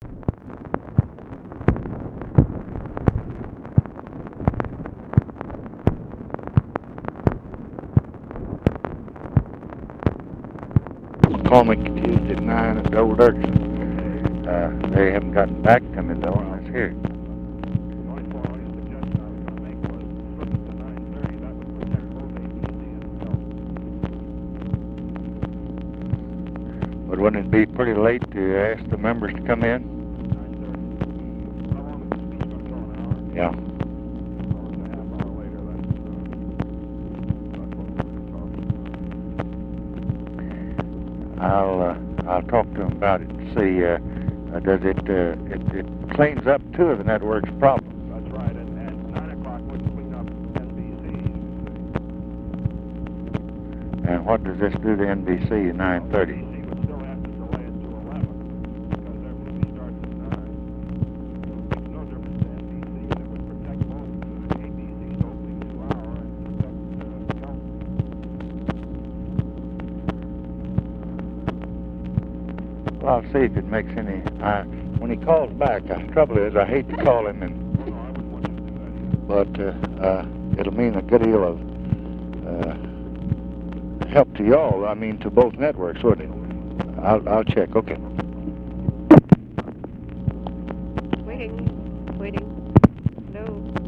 Conversation with FRANK STANTON, January 7, 1967
Secret White House Tapes